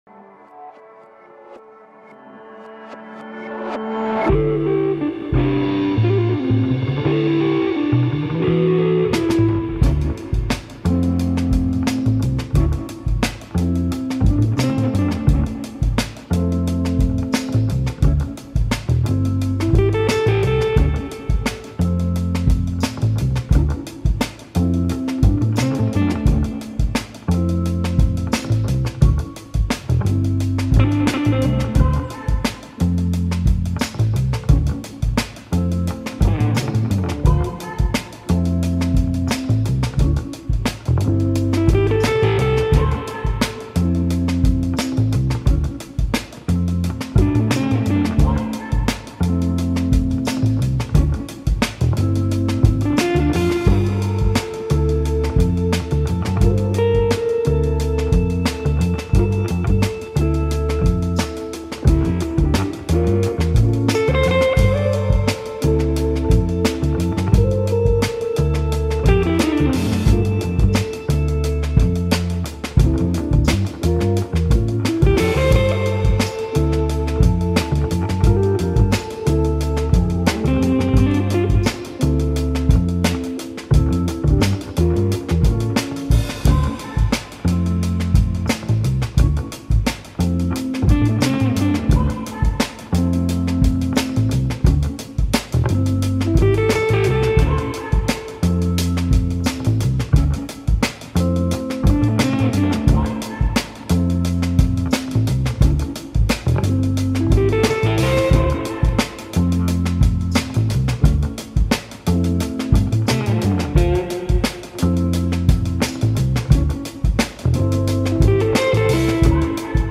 Psychedelic Funk, Thai Funk, Surf Rock, Soul